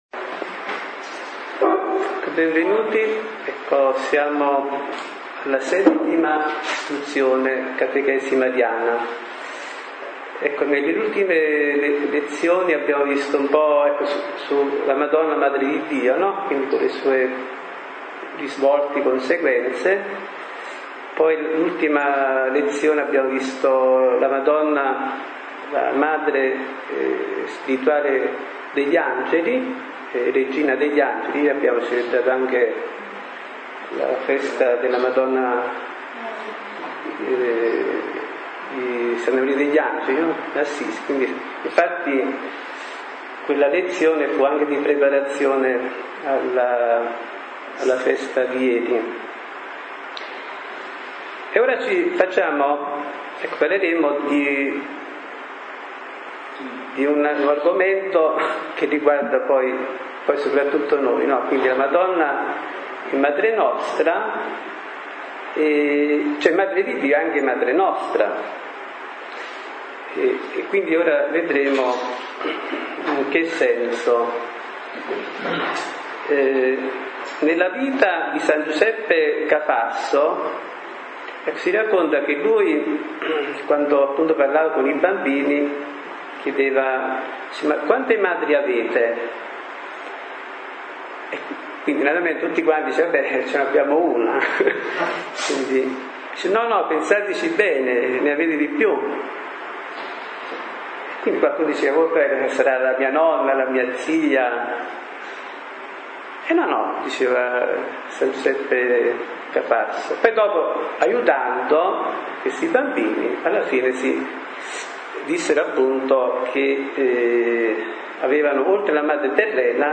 Genere: Catechesi domenicali.